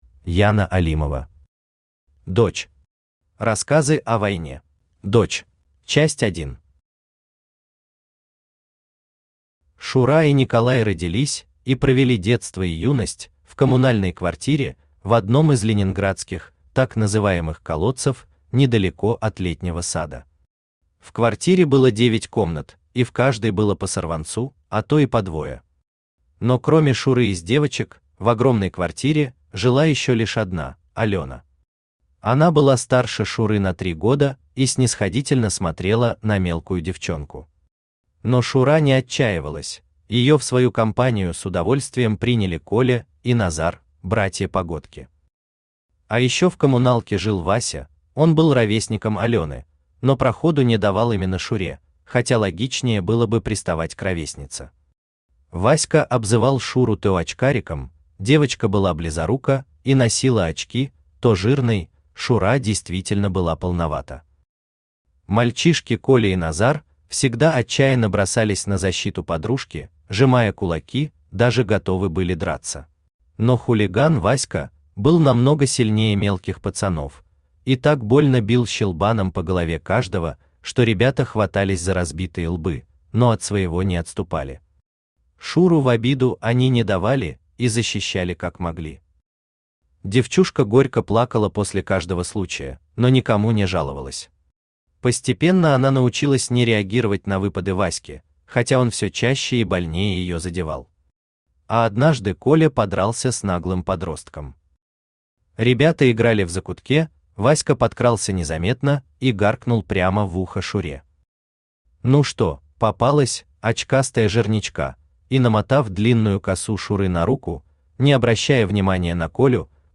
Аудиокнига Дочь. Рассказы о войне | Библиотека аудиокниг
Рассказы о войне Автор Яна Алимова Читает аудиокнигу Авточтец ЛитРес.